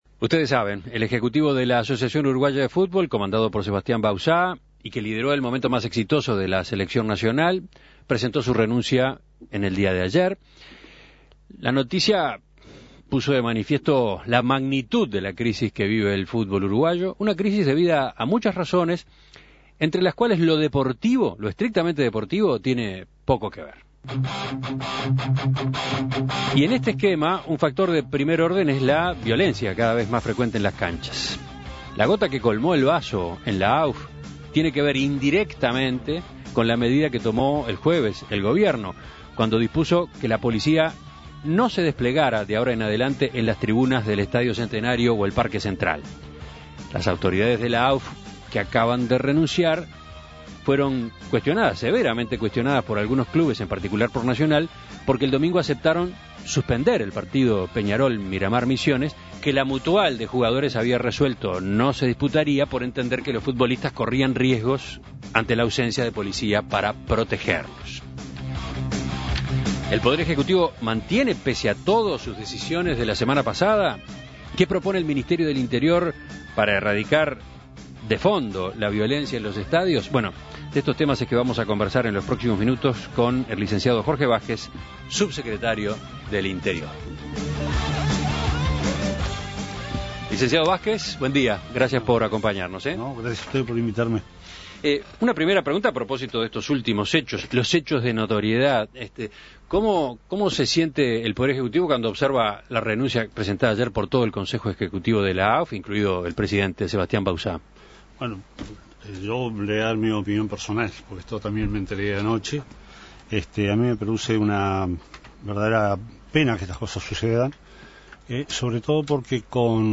En Perspectiva dialogó con el subsecretario del Interior, Jorge Vázquez, sobre el impacto de la decisión tomada la semana pasada y posibles nuevas medidas.